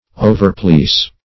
Overplease \O`ver*please"\, v. t. To please excessively.